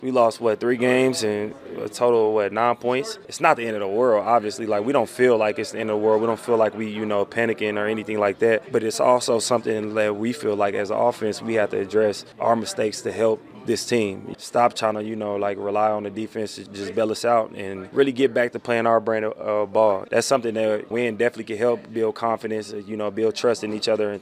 Game changer: Running back Josh Jacobs talked about how one game can change a team’s momentum.